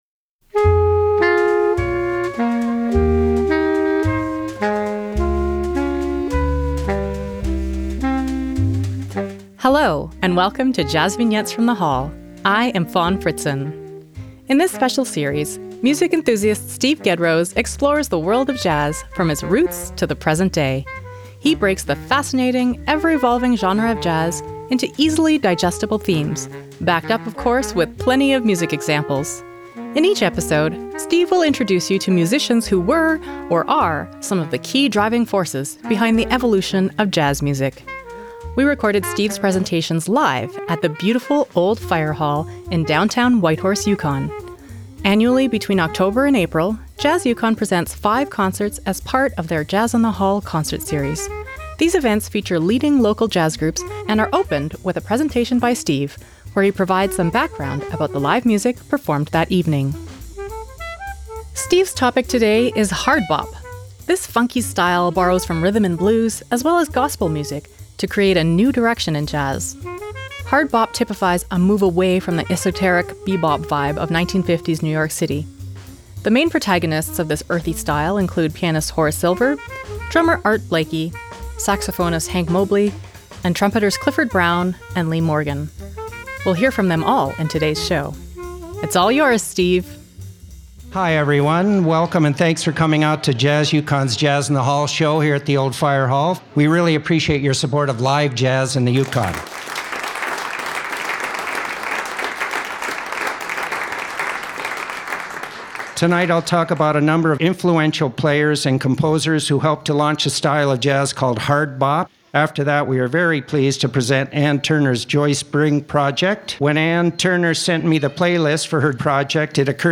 jvfth20HardBop.mp3